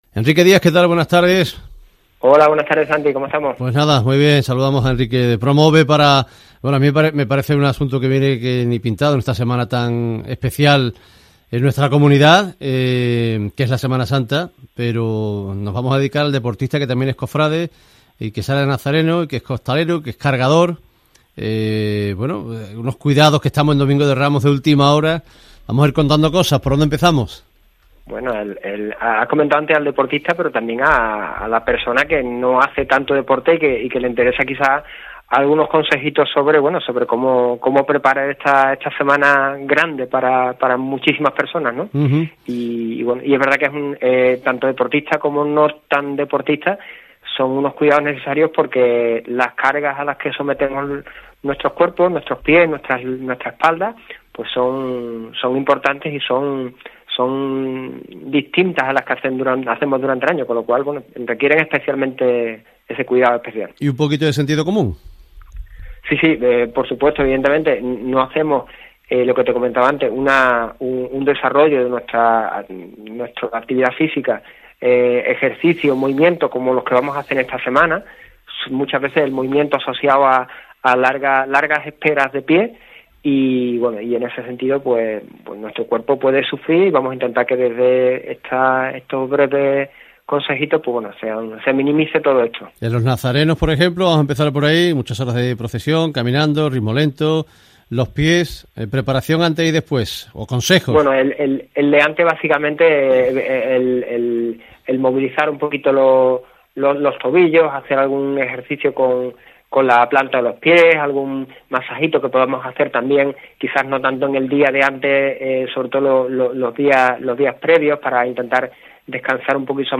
En la matinal de los domingos en Ser Deportivos Andalucía